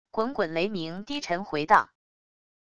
滚滚雷鸣低沉回荡wav音频